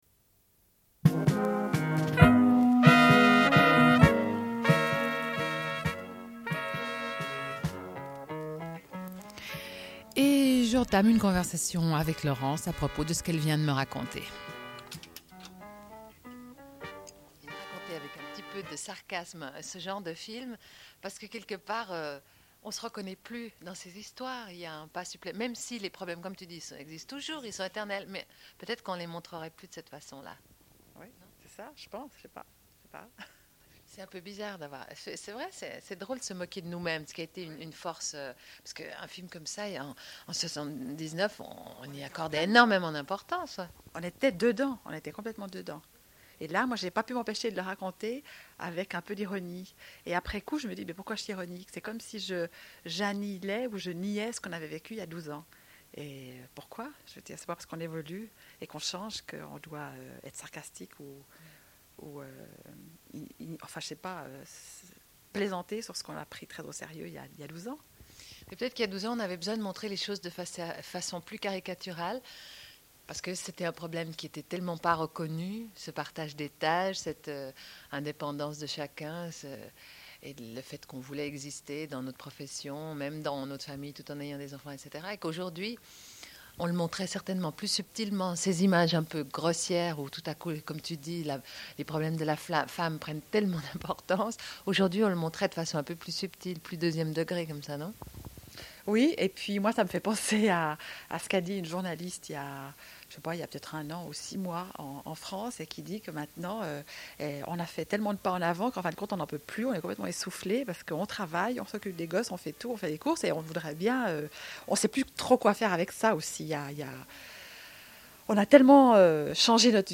Une cassette audio, face B29:00